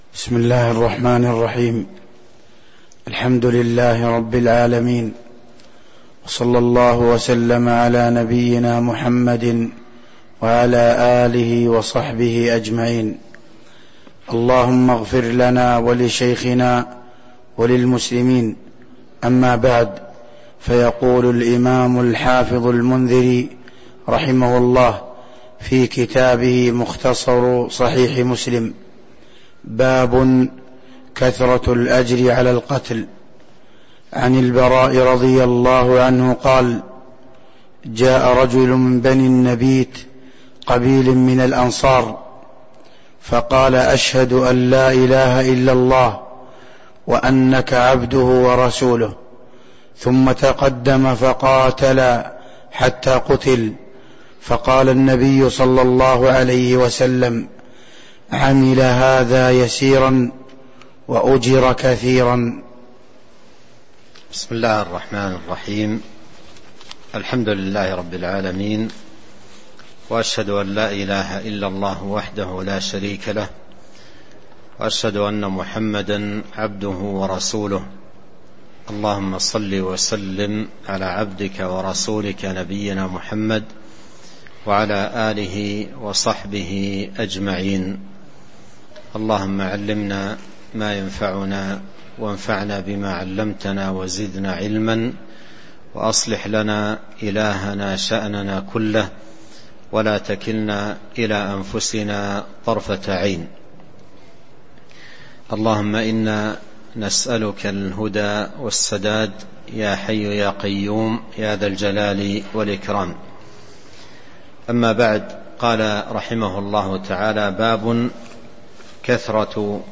تاريخ النشر ٢٧ ربيع الأول ١٤٤٣ هـ المكان: المسجد النبوي الشيخ: فضيلة الشيخ عبد الرزاق بن عبد المحسن البدر فضيلة الشيخ عبد الرزاق بن عبد المحسن البدر باب كثرة الأجر على القتال (07)